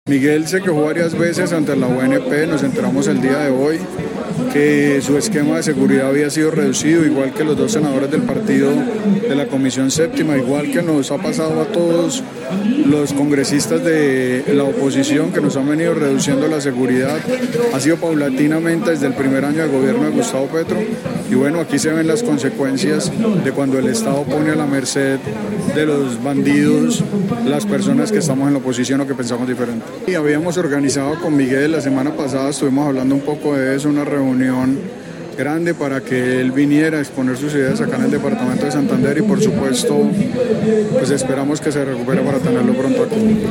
Óscar Villamizar, representante a la Cámara por Santander
Durante la velatón que se realizó en el parque San Pío en Bucaramanga el congresista Óscar Villamizar manifestó su rechazo por la falta de seguridad que tenía el senador Miguel Uribe Turbay.